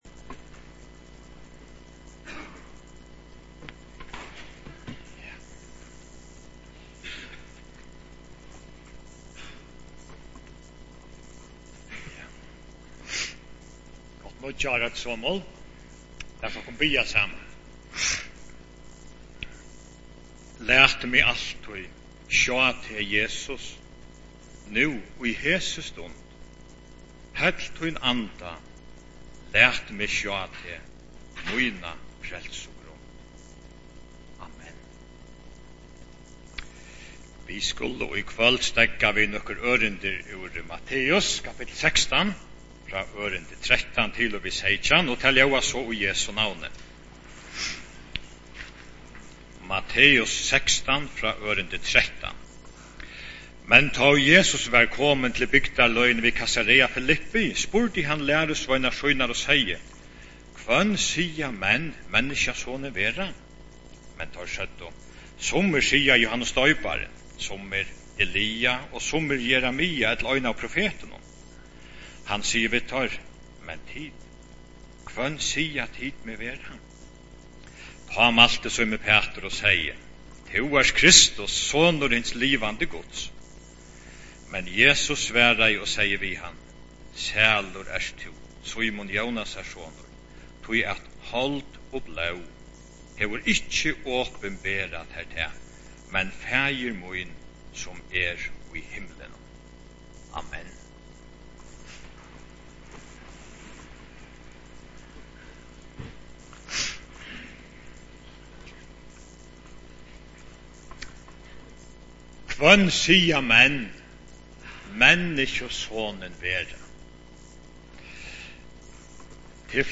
Leirvíkar Missiónshús